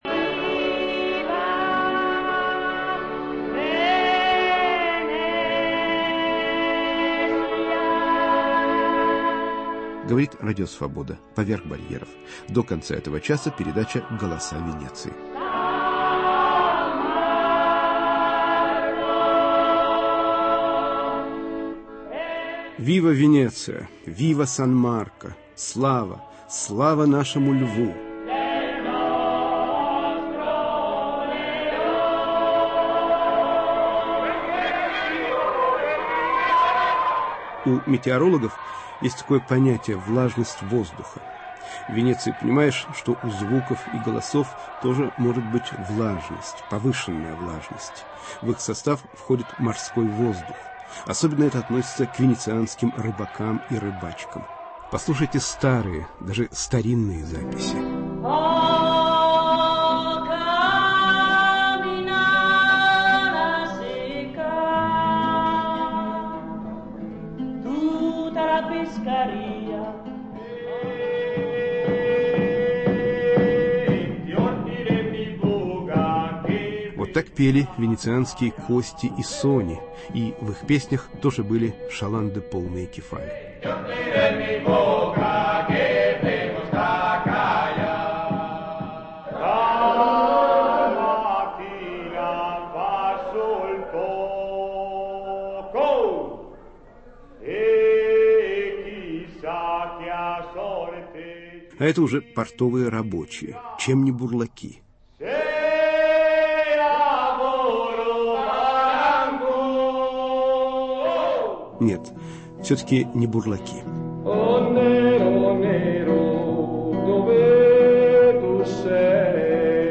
"Голоса Венеции" - акустический портрет города (люди, городской фольклор,